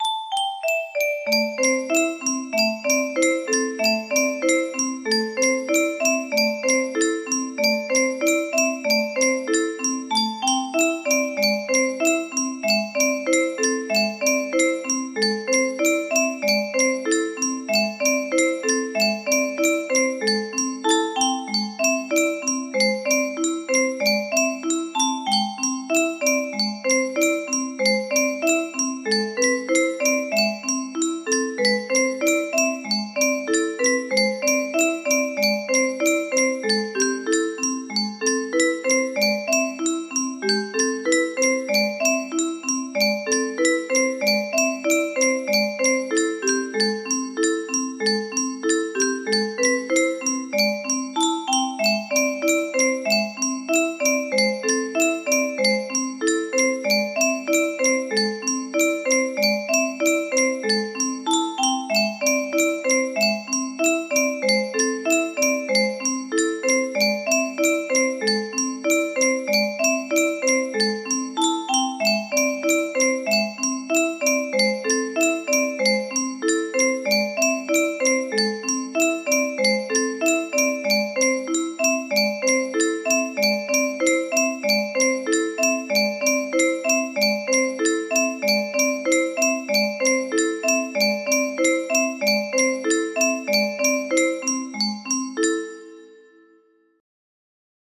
Full range 60
Music box rearrangement